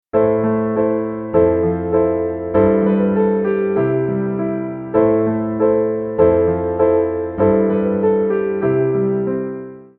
Am　→　F　→　G　→　C
シンプルながら力強く分かりやすいですね。